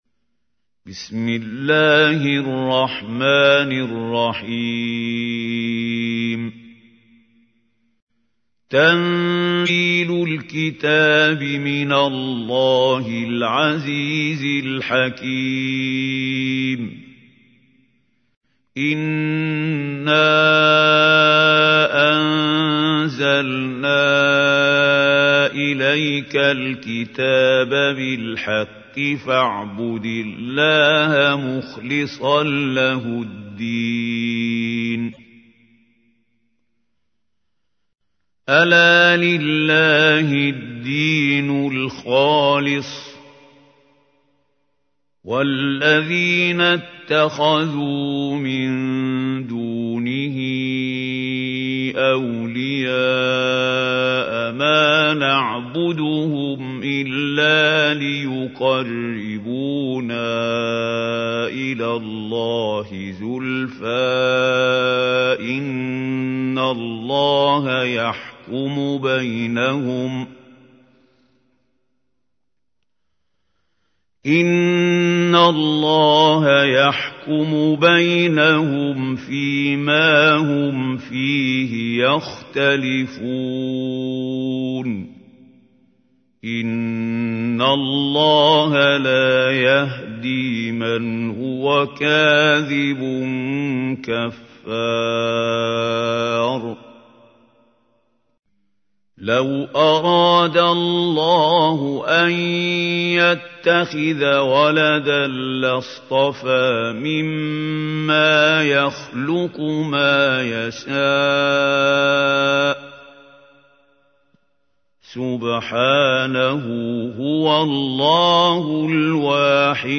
تحميل : 39. سورة الزمر / القارئ محمود خليل الحصري / القرآن الكريم / موقع يا حسين